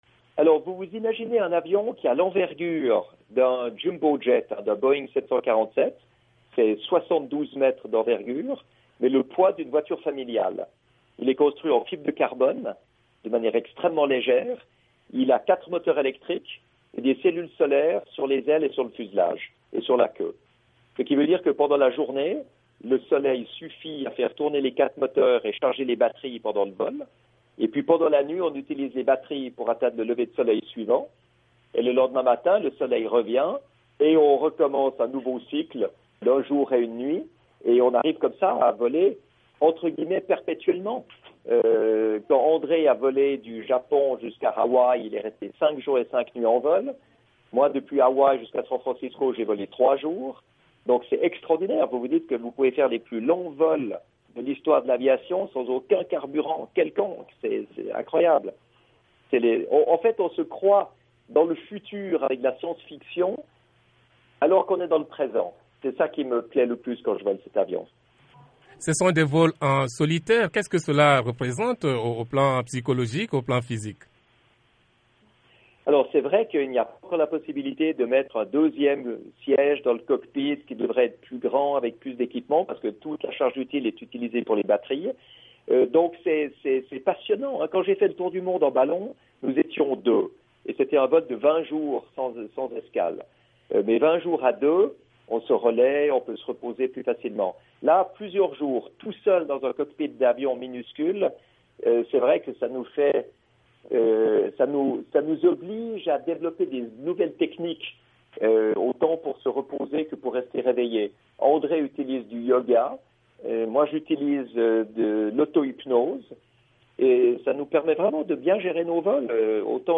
Dans un entretien accordé à VOA Afrique à partir de Dayton, dans l’Etat américain de l’Ohio, Bertrand Piccard, a rappelé qu’il vient d’une famille d’explorateurs.
Entretien avec Bertrand Piccard (2)